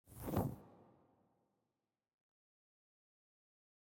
open_bag_master_1.wav